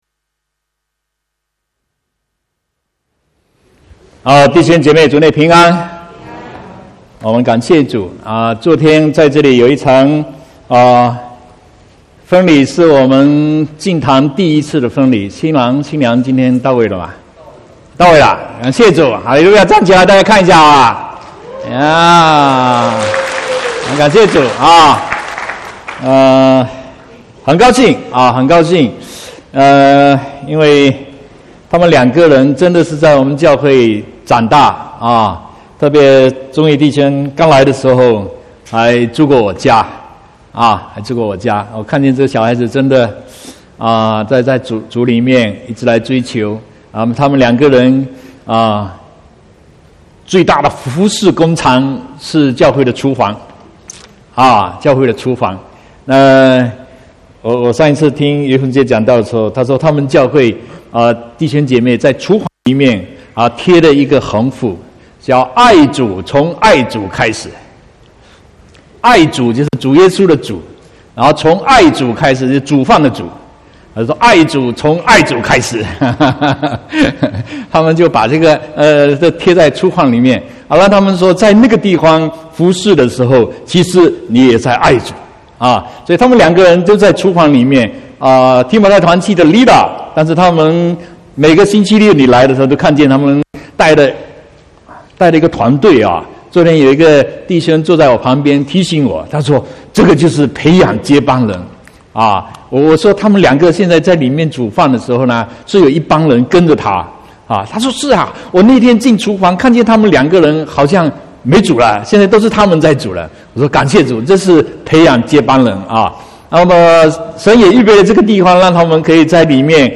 13/1/2019 國語堂講道